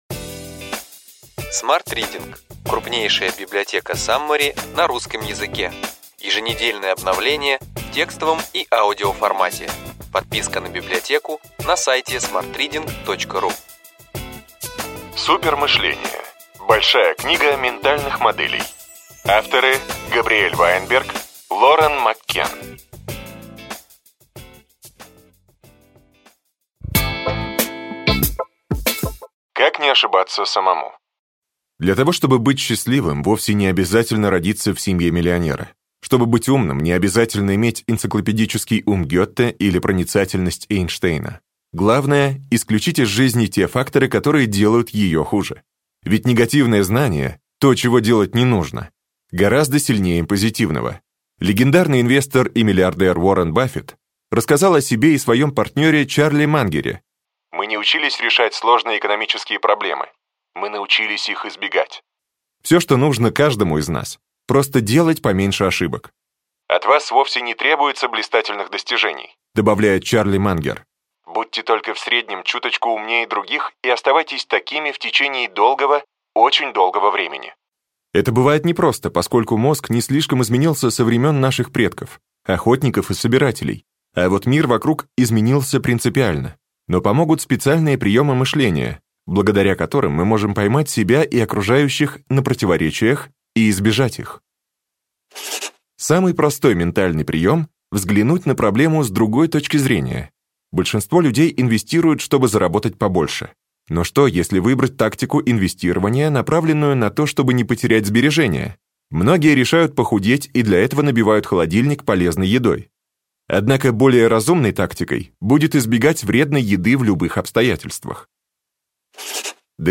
Аудиокнига Супермышление. Большая книга ментальных моделей.